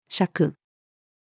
酌-female.mp3